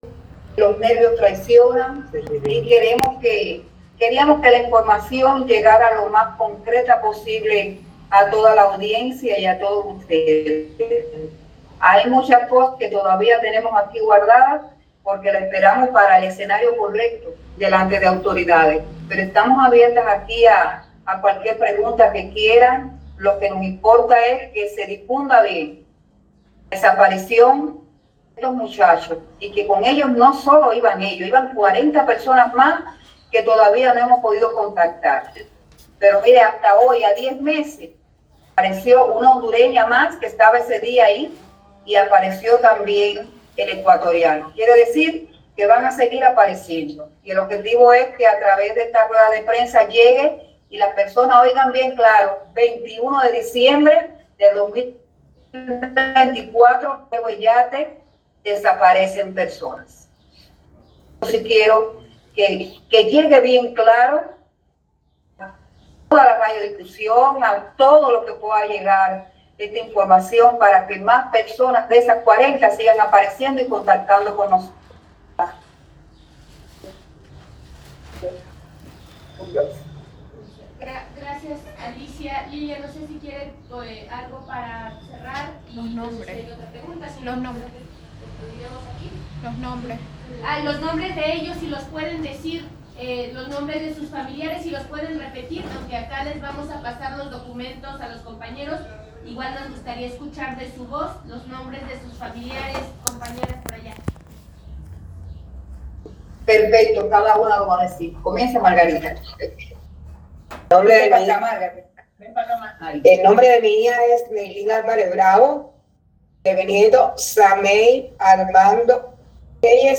Aquí pueden escuchar parte del mensaje de las madres centroamericanas, así como el nombre de sus amores desaparecidos en Chiapas: